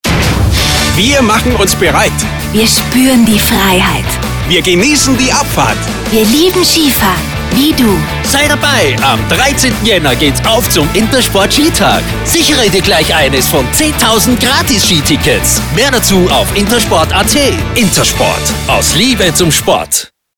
Den aktuellen Intersport-Spot können Sie